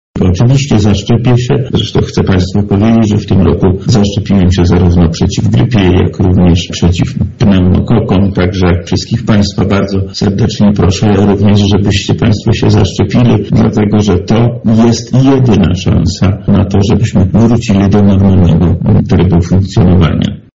• mówi Wojewoda Lubelski Lech Sprawka